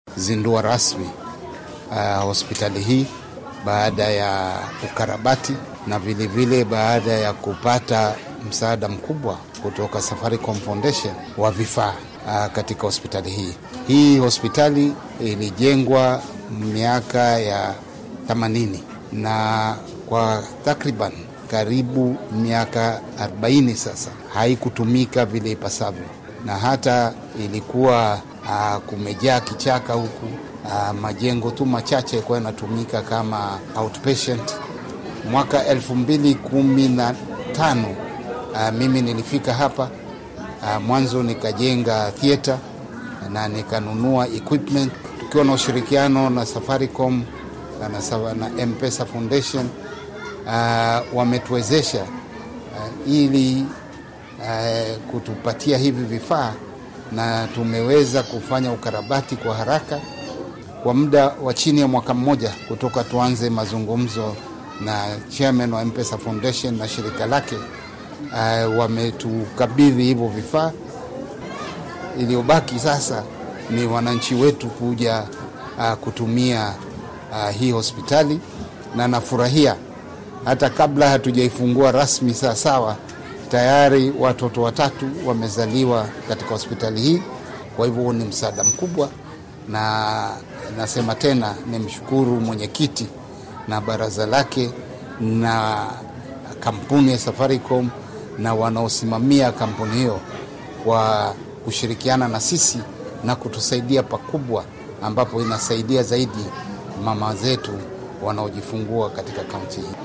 Barasaabka Lamu Issa Timamy oo arrimahan ka hadlaya ayaa yiri.